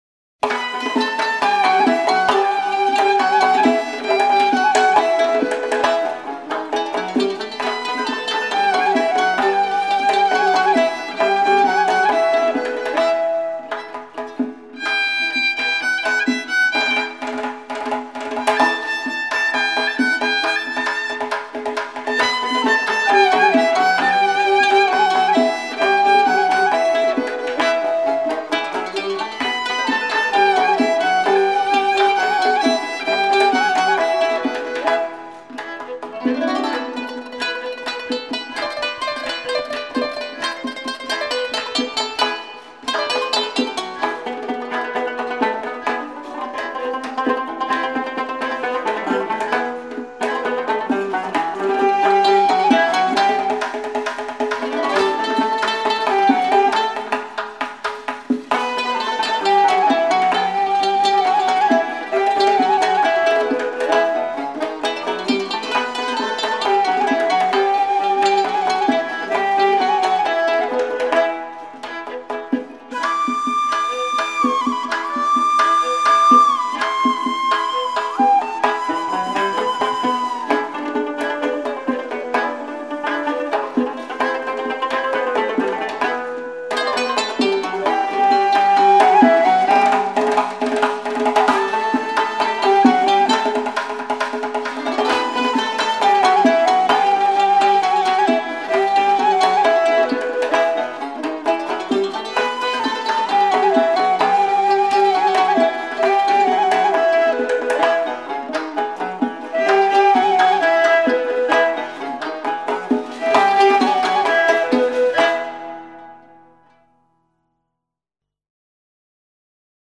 色彩缤纷  热情洋溢的丝绸歌调
塔吉克族的民间音乐，以奔放、丰富的色彩最让人赞叹不已。
专辑中实地收录的十六首乐曲，皆为实实在在的塔吉克情歌、民歌与圆舞曲，